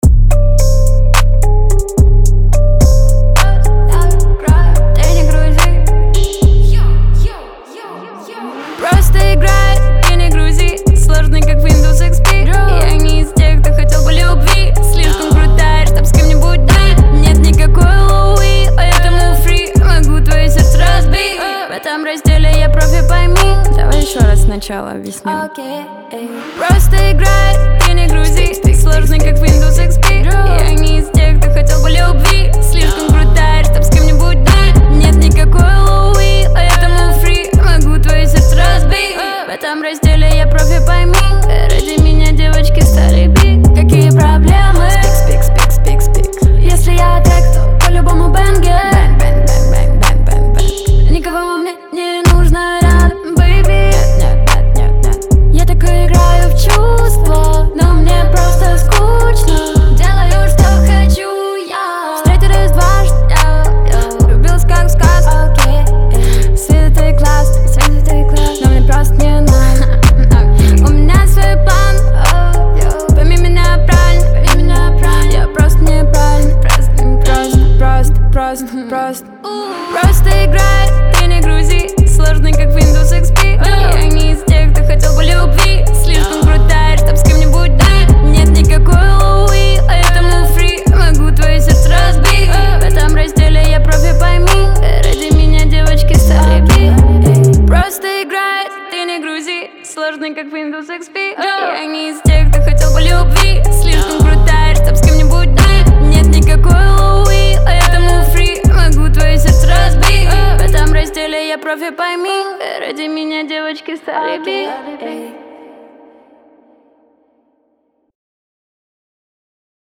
это яркий трек в жанре синти-поп